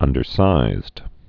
(ŭndər-sīzd) also un·der·size (-sīz)